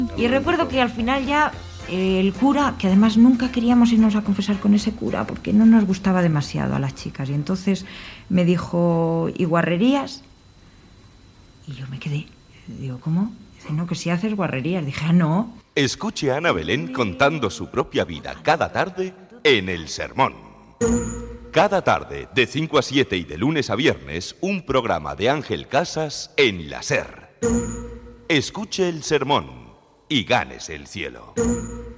Promoció del programa i de l'entrevista per capítols a l'actriu Ana Belén (María Pilar Cuesta)
Programa presentat per Àngel Casas.